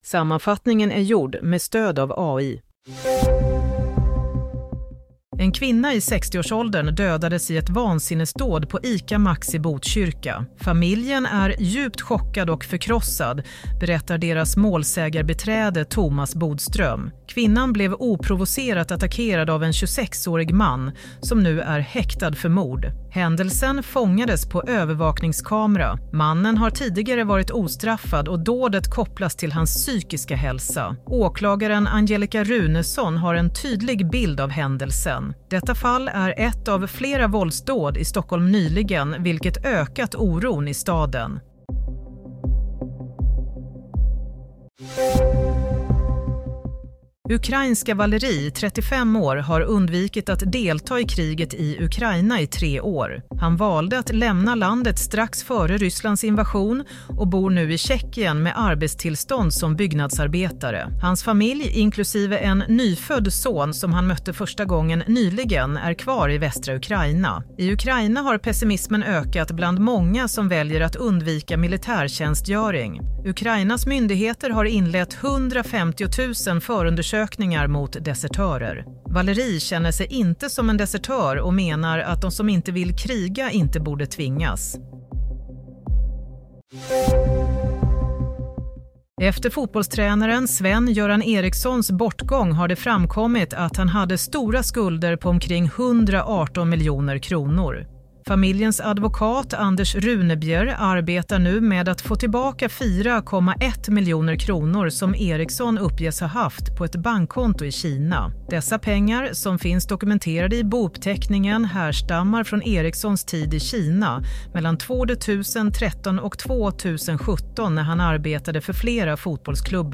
Nyhetssammanfattning - 28 januari 07:00
Sammanfattningen av följande nyheter är gjord med stöd av AI.